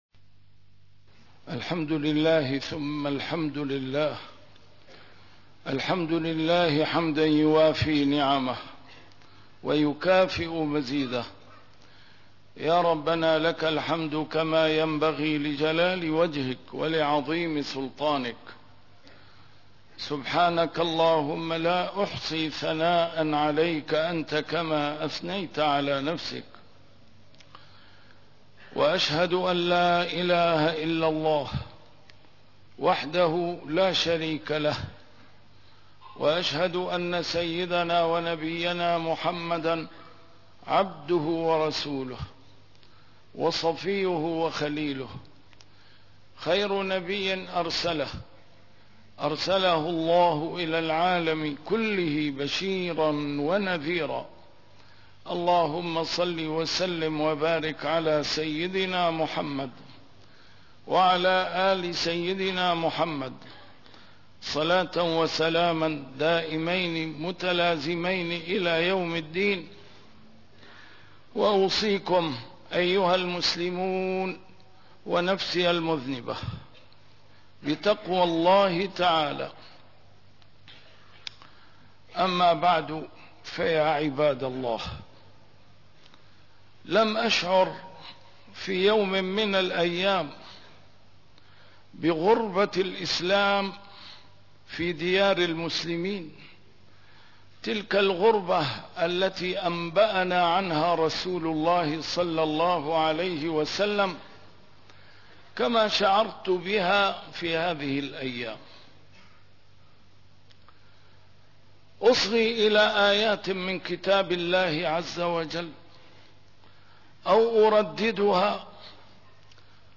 A MARTYR SCHOLAR: IMAM MUHAMMAD SAEED RAMADAN AL-BOUTI - الخطب - غربة الإسلام